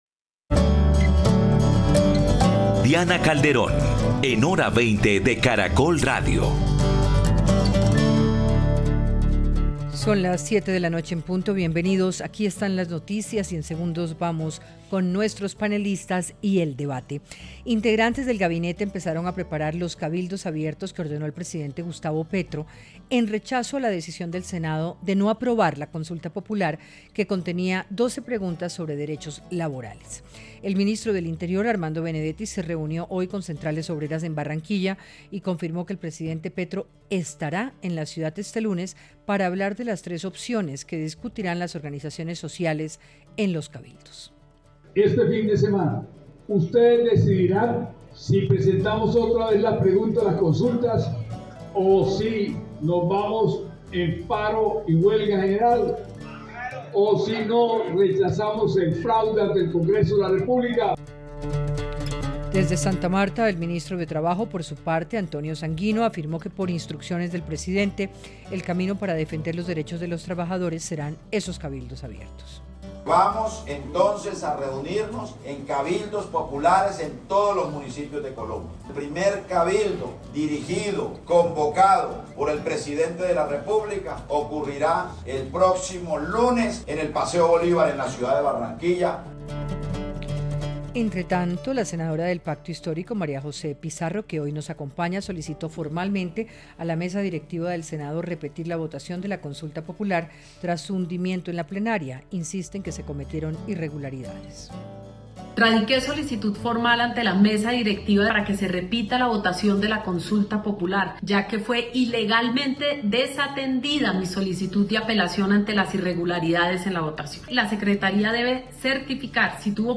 Panelistas analizaron la situación en la que quedó el país y los distintos escenarios institucionales y de movilización social que se han instalado tras el hundimiento de la Consulta Popular.